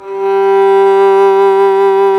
Index of /90_sSampleCDs/Roland L-CD702/VOL-1/STR_Violin 1 vb/STR_Vln1 _ marc
STR VLN MT00.wav